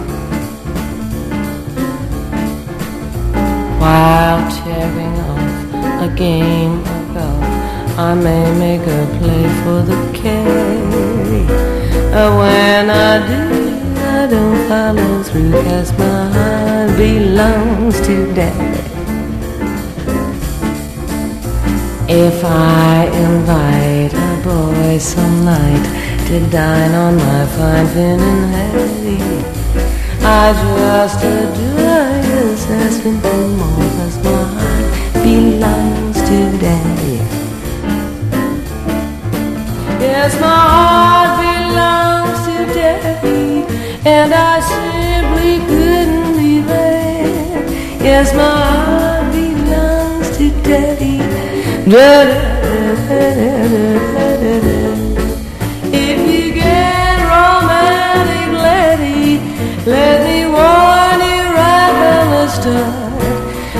JAZZ / JAZZ ROCK / FUSION
サンプリング・ネタ多数のジャズ・ロック隠れ名盤！
スペイシーなシンセ・ワークと緻密なバンド・アンサンブルが融合したヨーロピアン・ジャズ・ロックの傑作です。